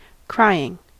Ääntäminen
IPA : /kɹaɪ̯.ɪŋ/